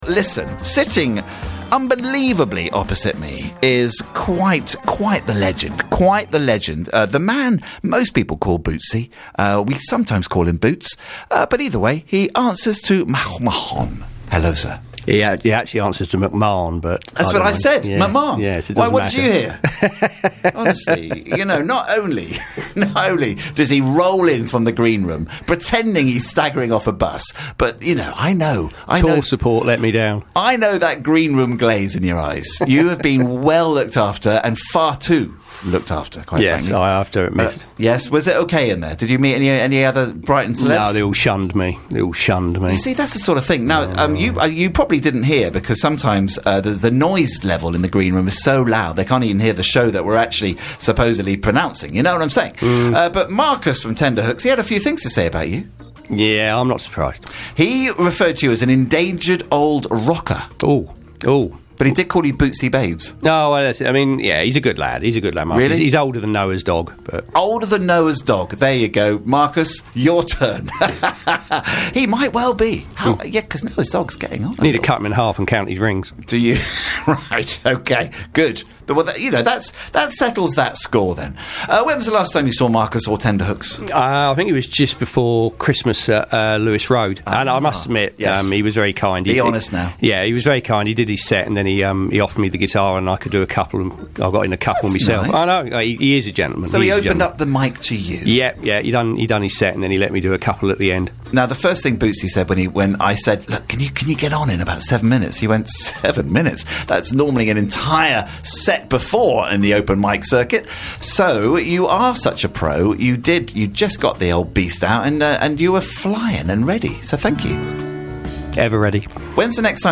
4 live tracks and some great chat.